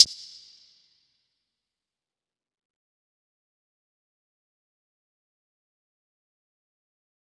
Closed Hats
DMV3_Hi Hat 1.wav